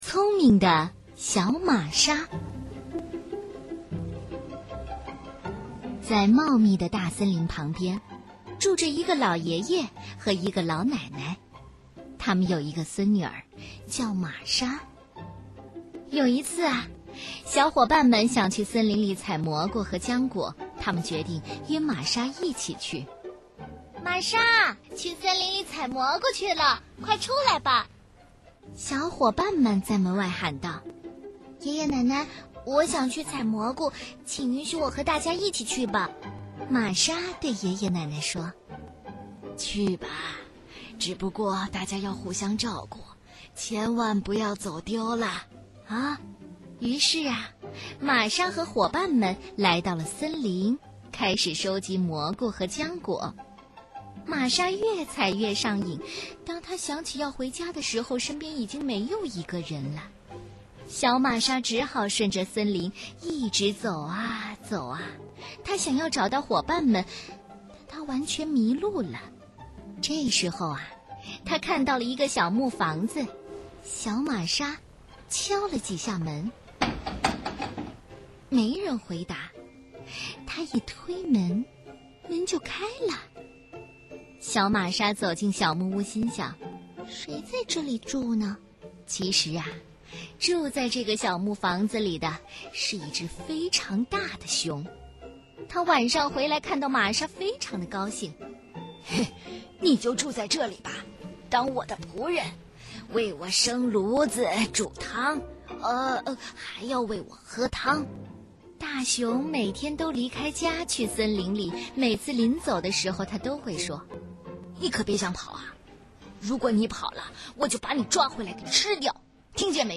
首页>mp3 > 儿童故事 > 聪明的小玛莎